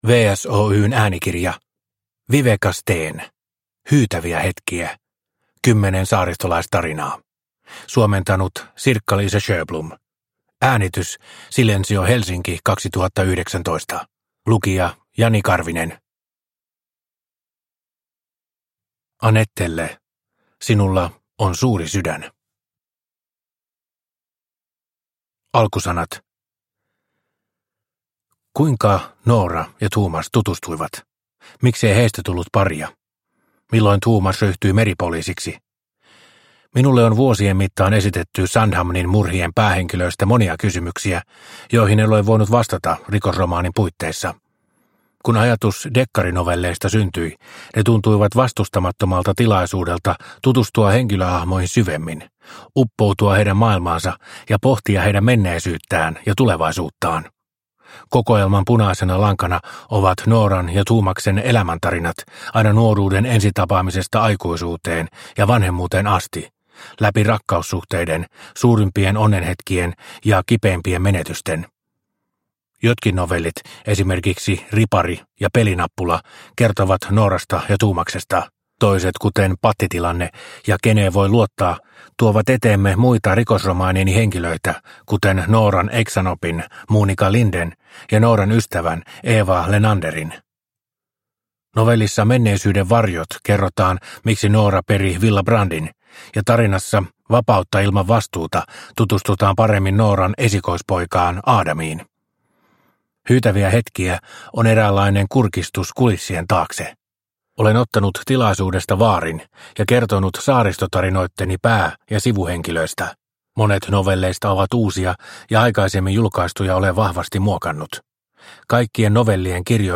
Hyytäviä hetkiä – Ljudbok – Laddas ner